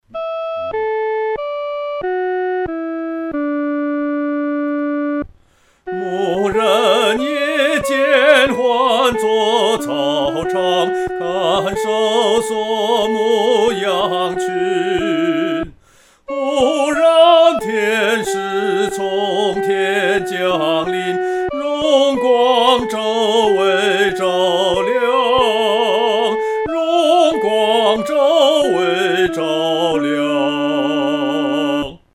独唱（第一声）
牧人闻信-独唱（第一声）.mp3